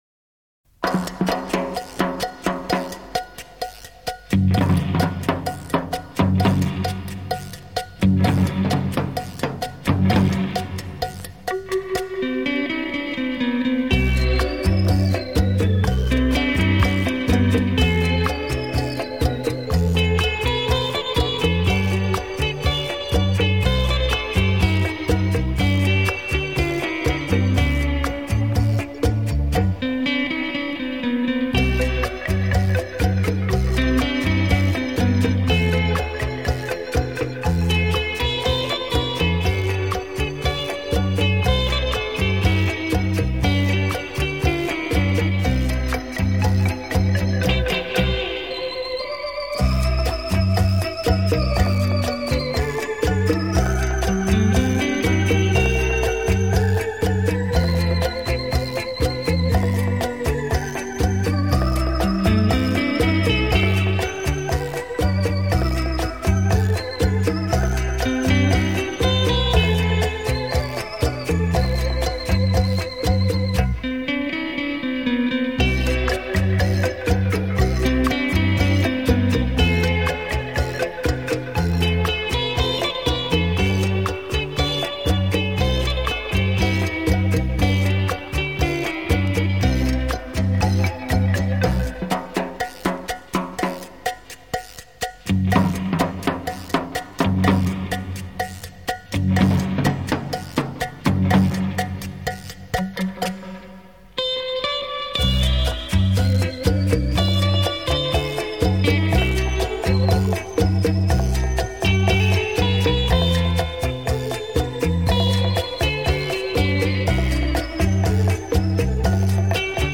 chacha之声
60年代最流行吉打音乐